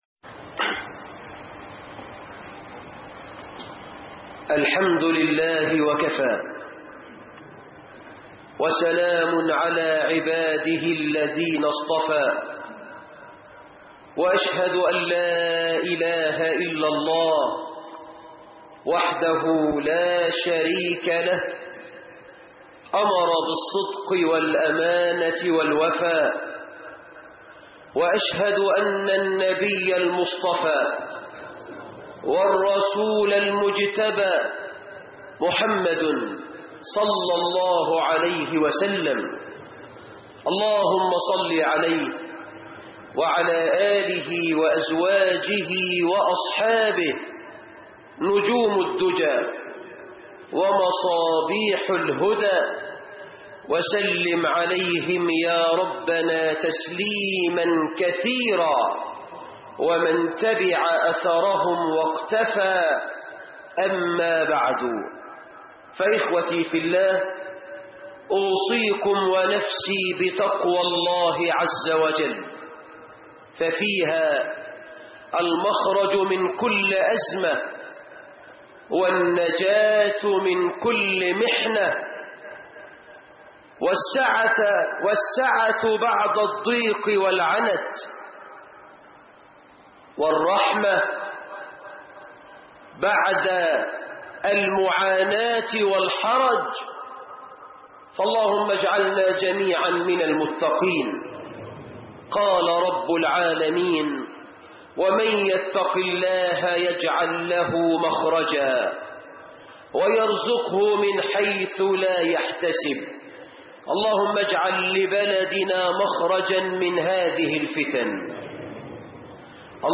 المحرمات الستة التى يقع فيها الناس - خطب الجمعه